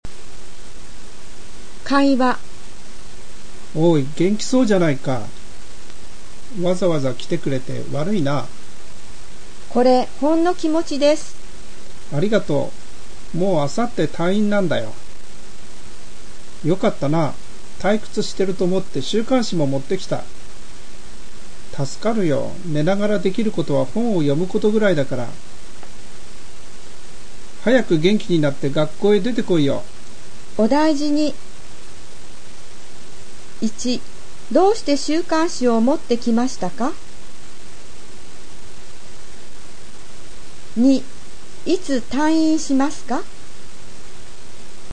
【会話】(conversation)